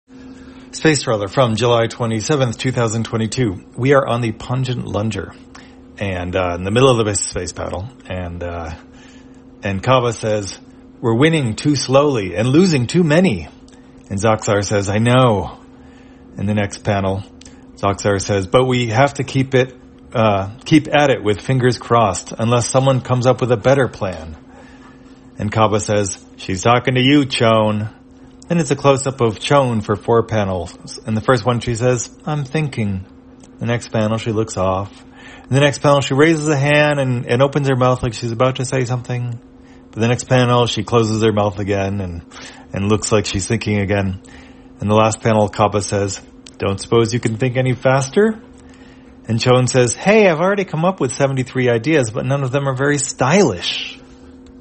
Spacetrawler, audio version For the blind or visually impaired, July 27, 2022.